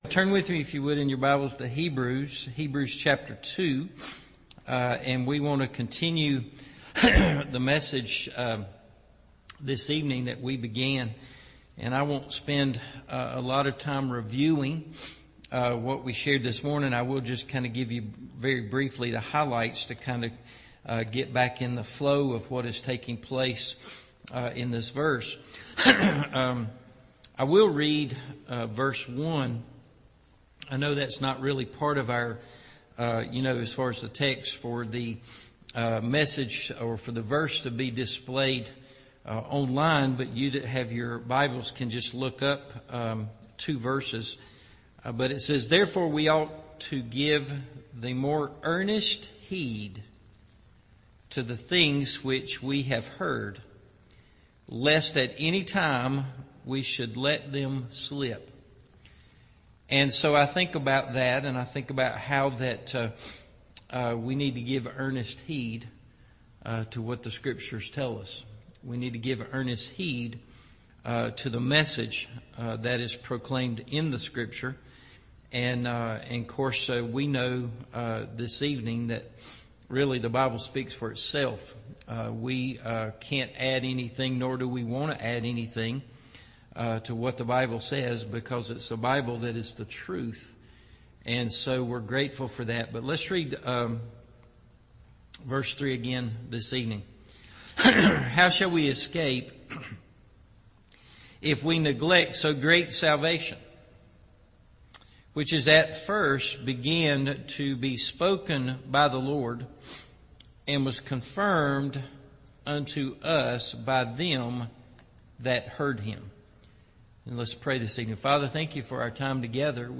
How Shall We Escape If We Neglect So Great Salvation Part 2 – Evening Service